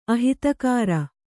♪ ahitakāra